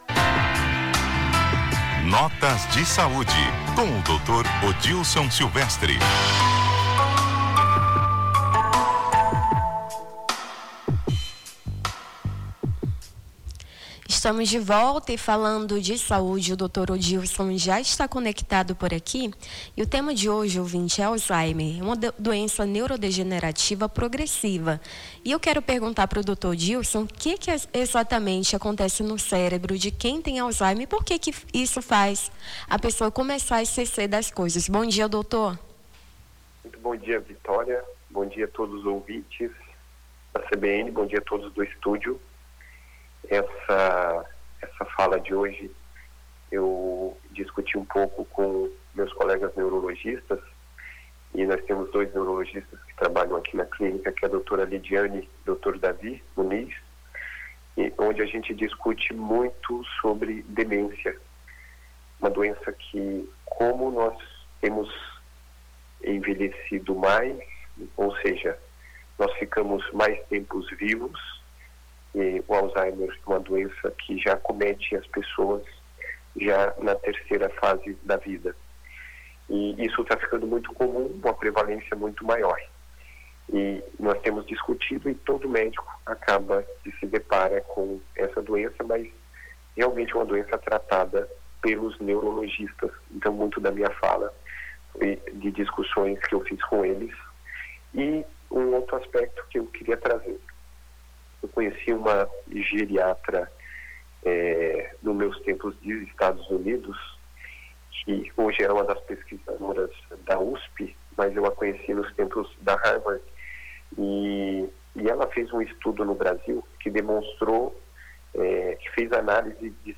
Nome do Artista - CENSURA - COLUNA (NOTAS DE SAUDE) 09-12-25.mp3